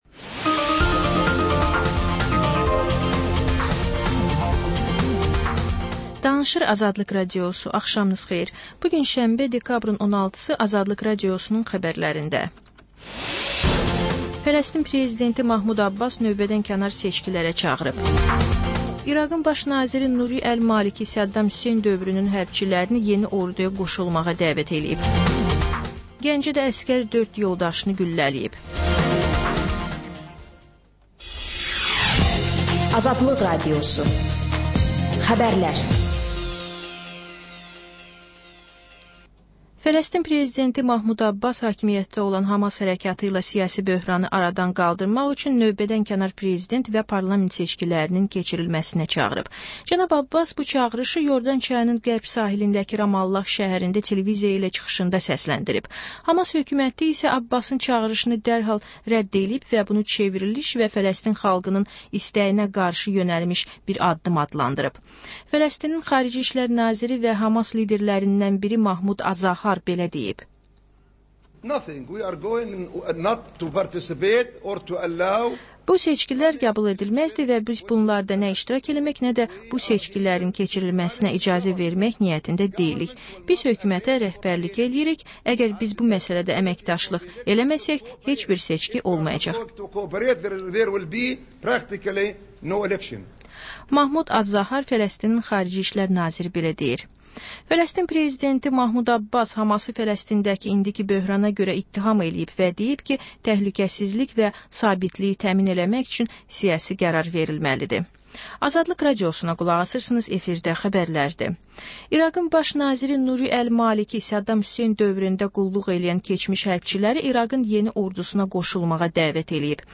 Xəbərlər, reportajlar, müsahibələr. Panorama: Jurnalistlərlə həftənin xəbər adamı hadisələri müzakirə edir.